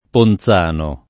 ponZ#no] top. — quattro comuni: P. Monferrato (Piem.); P. Veneto (Ven.); P. di Fermo (Marche); P. Romano (Lazio); e altri luoghi in varie regioni (E.-R., Lig., Tosc., Abr.) — sim. i cogn. Ponzani, Ponzano — cfr. Ponsano